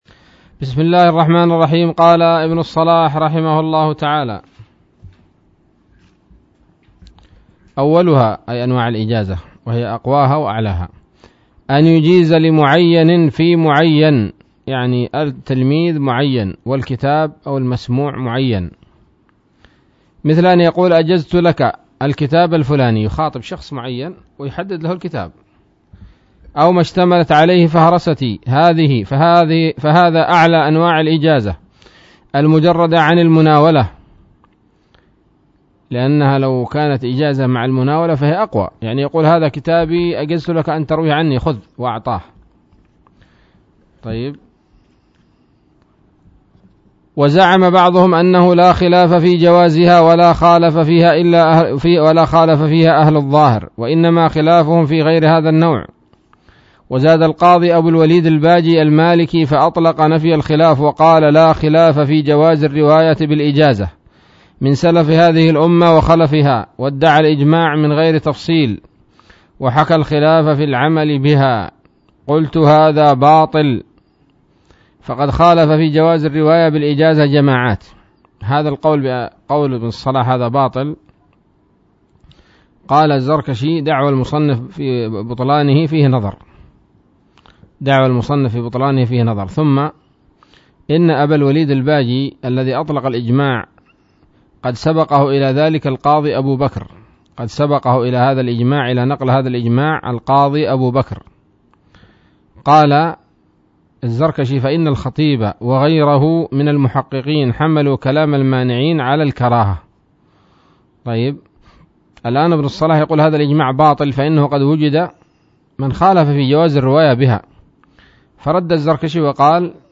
الدرس السادس والستون من مقدمة ابن الصلاح رحمه الله تعالى